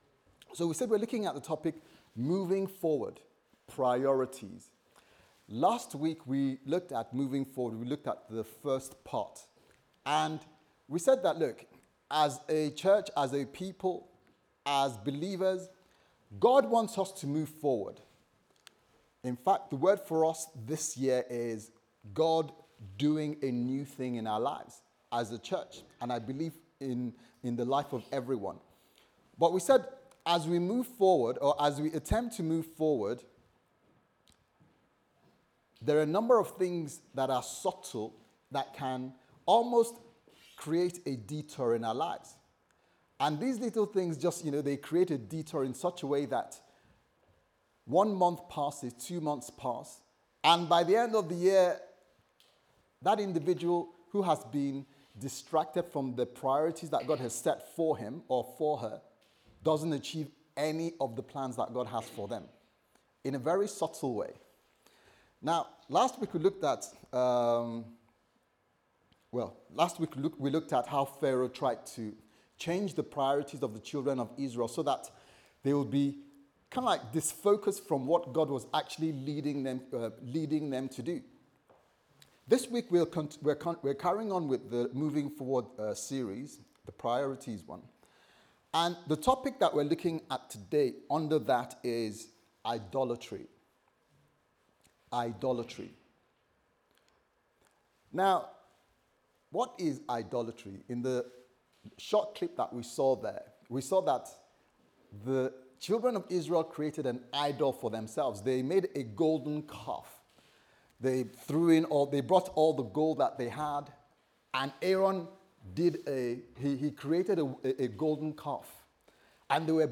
Priorities Service Type: Sunday Service Sermon « Moving Forward